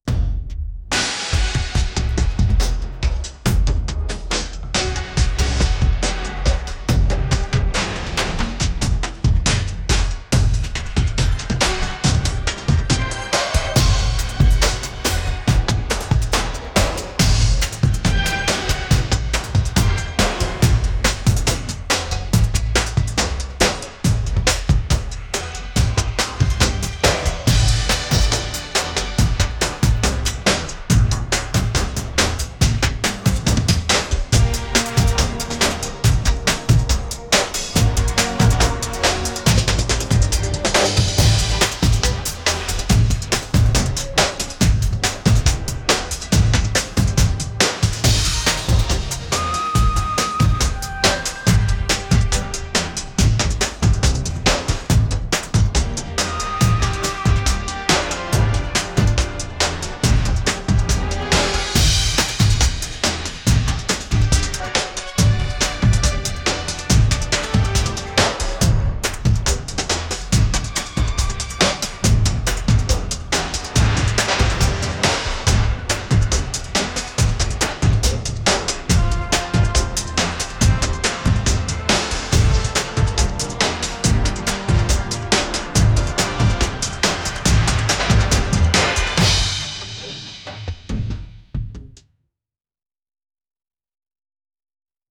music
action game theme_3.wav